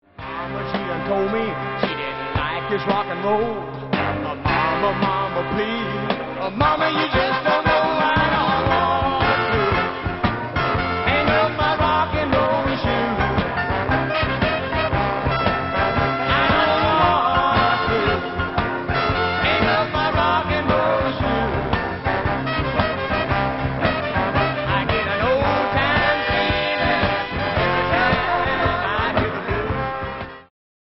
historical country rock, mixed with gospel and blues
(recorded live)